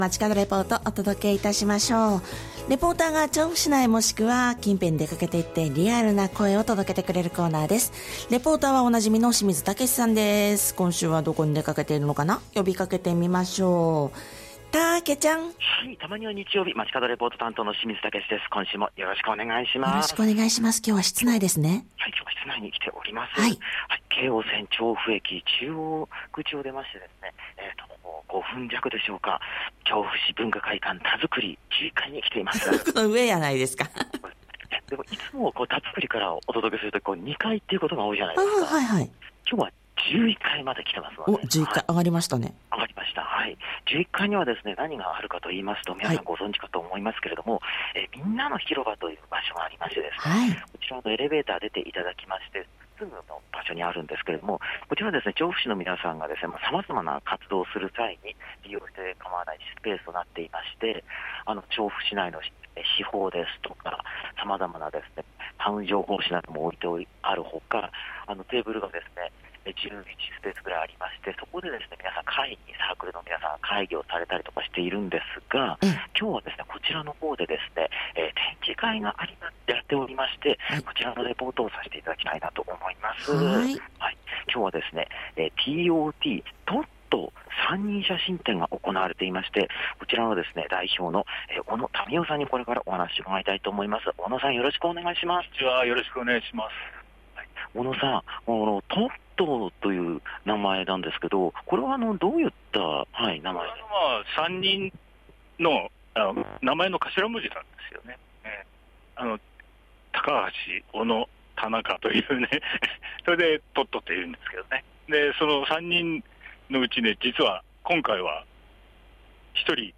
久々の雨が降る中お届けした本日の街角レポートは、文化会館たづくり11階・みんなの広場で行われている「tot3人写真展」の会場からのレポートです！！